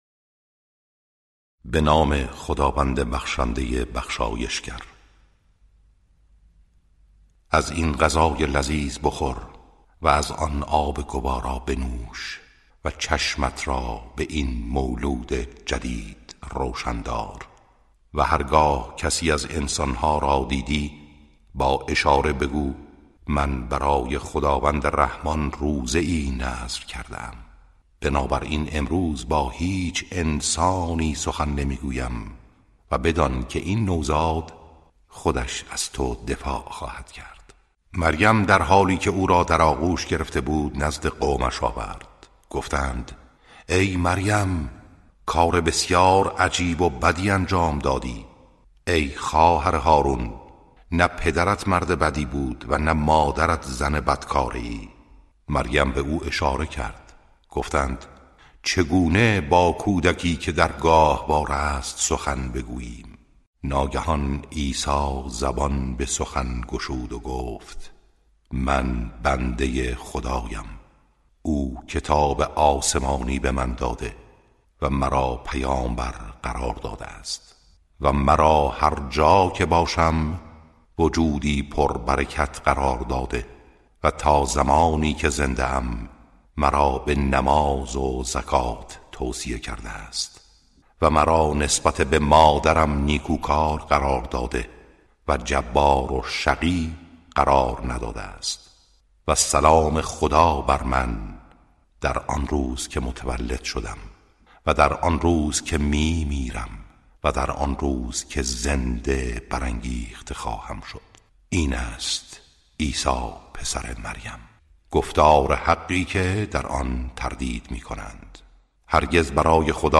ترتیل صفحه ۳۰۷ سوره مبارکه مریم(جزء شانزدهم)